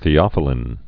(thē-ŏfə-lĭn)